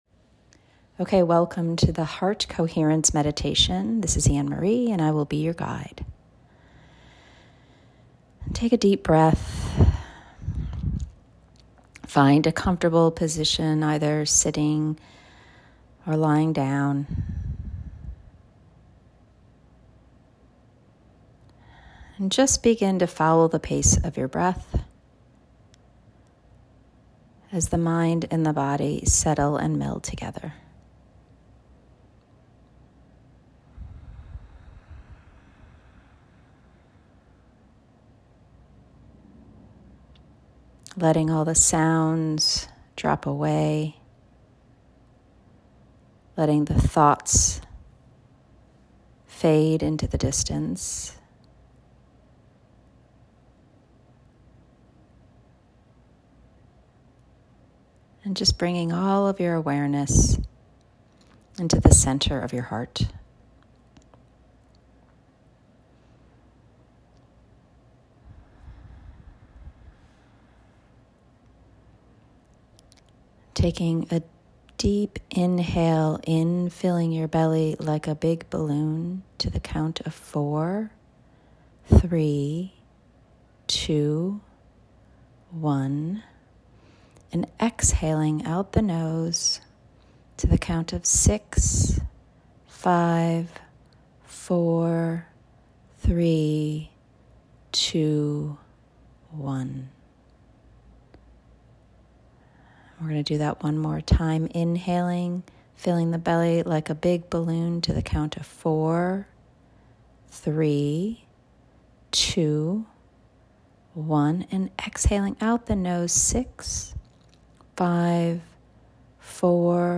Heart CoHerence Meditation
Heart-CoHerence-Meditation.m4a